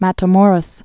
(mătə-môrəs, mätä-mōrōs)